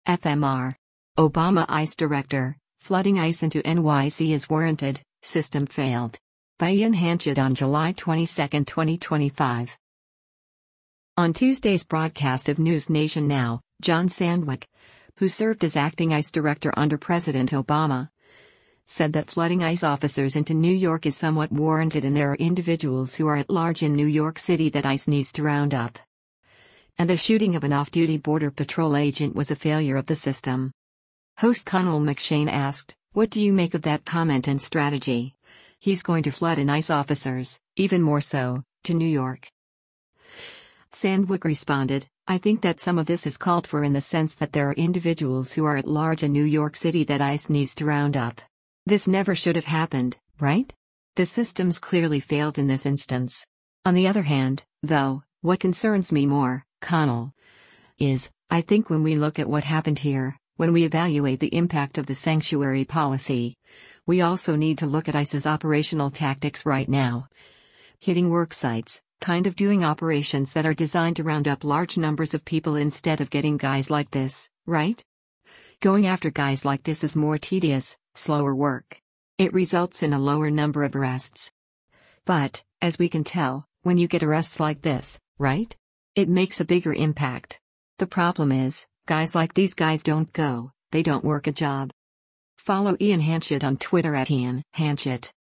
On Tuesday’s broadcast of “NewsNation Now,” John Sandweg, who served as acting ICE Director under President Obama, said that flooding ICE officers into New York is somewhat warranted and “there are individuals who are at large in New York City that ICE needs to round up.” And the shooting of an off-duty Border Patrol agent was a failure of the system.